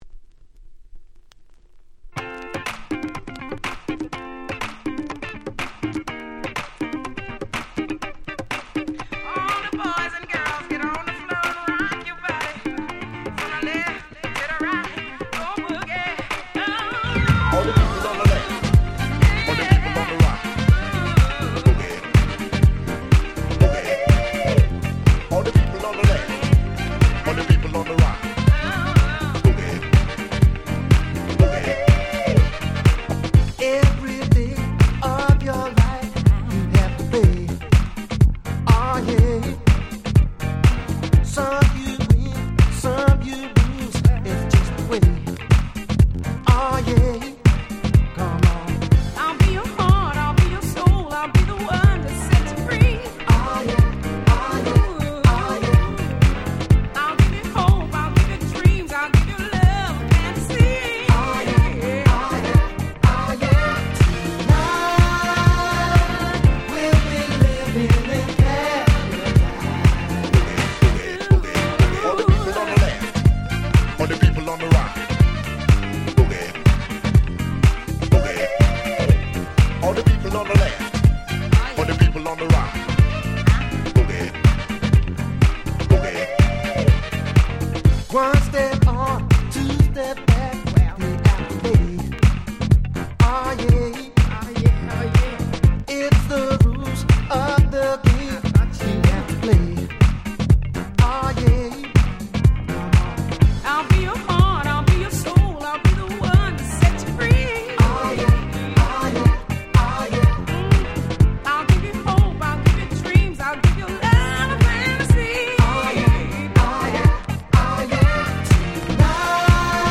98' Nice Acid Jazz/Vocal House !!
突き抜ける様な爽やかなVocalに攻撃的なLatinサウンドが鬼格好良すぎる超名曲！！